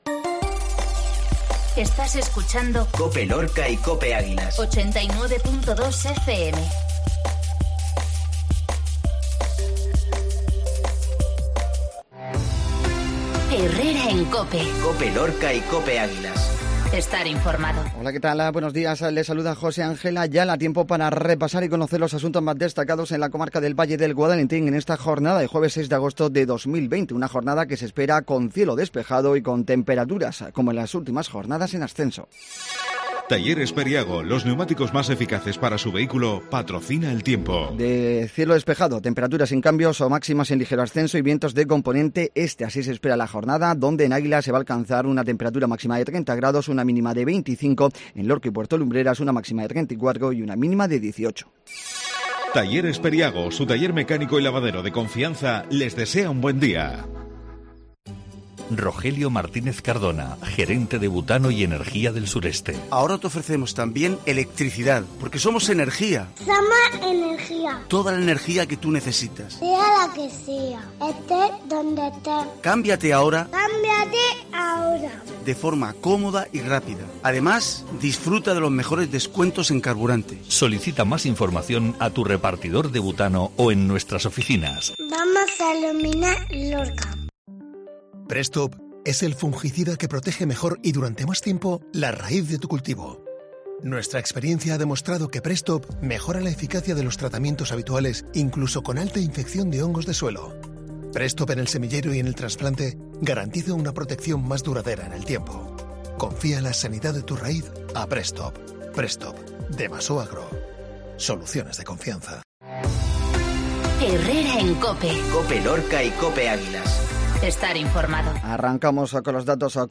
INFORMATIVO MATINAL COPE LORCA 0608